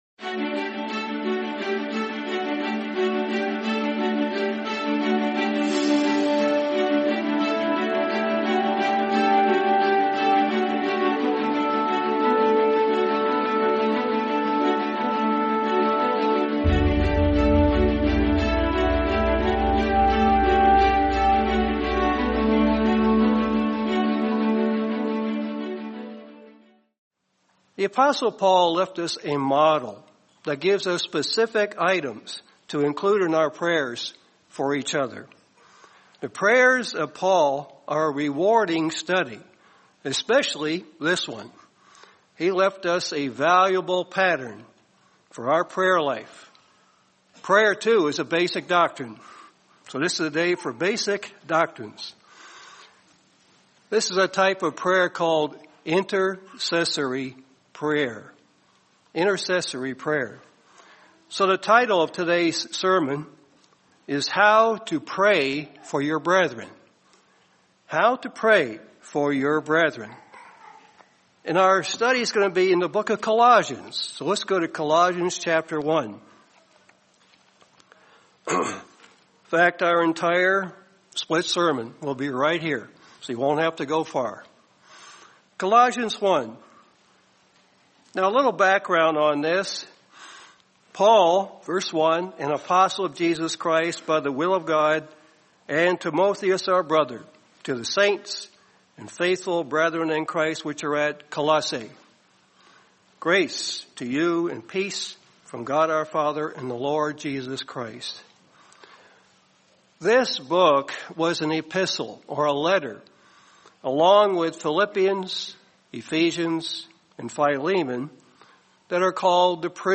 How to Pray for Your Brethren | Sermon | LCG Members